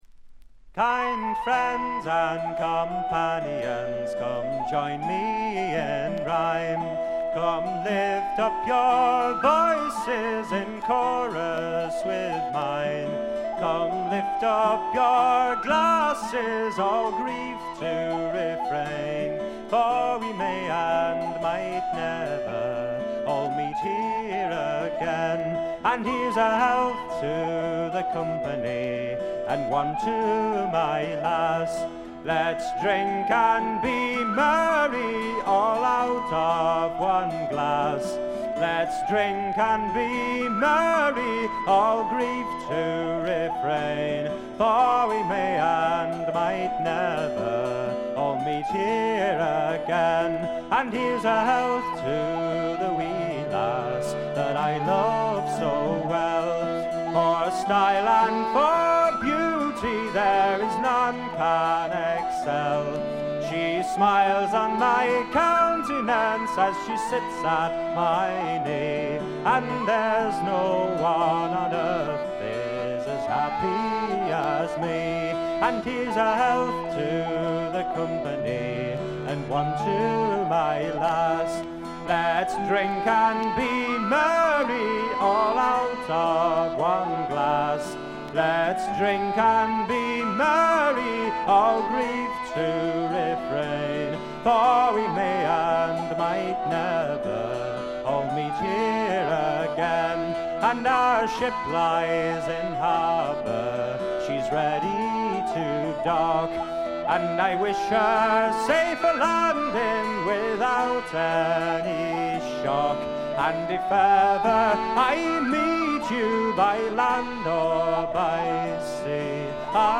見た目に反してバックグラウンドノイズやチリプチがそこそこ出ます。
本作のもの悲しい笛の音や寒そうな感覚は、おなじみのアイリッシュ・トラッドのような感じです。
アコースティック楽器のみで、純度の高い美しい演奏を聴かせてくれる名作です。
試聴曲は現品からの取り込み音源です。